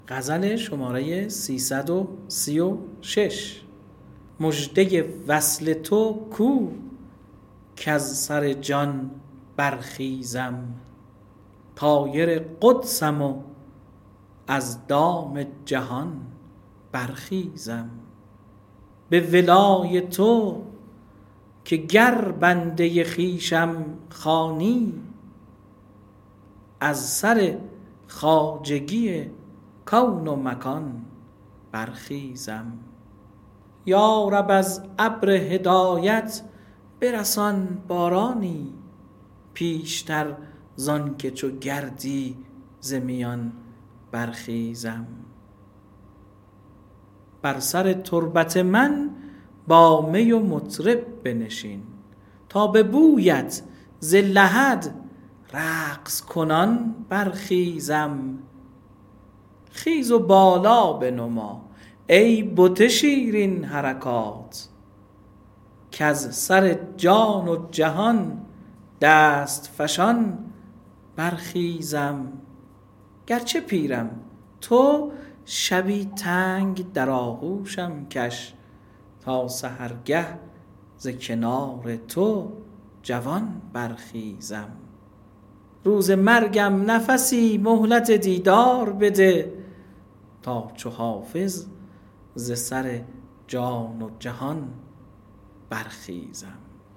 حافظ خوانی خردسالان | غزل شماره 336 | ادیب عشق